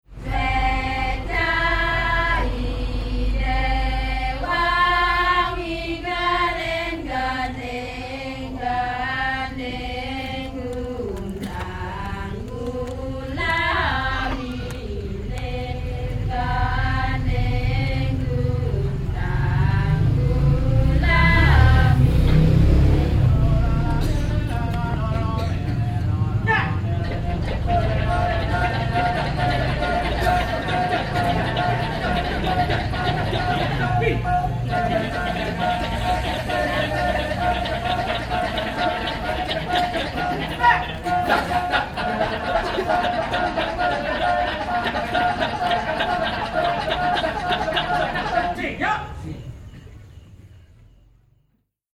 Alle Sounds gibt es in 2 Formaten, im RealAudio-Format in ausreichender Qualität und im MP3-Format in sehr guter Qualität.
6 Kecak-Dance Frauengesang
kecak2.mp3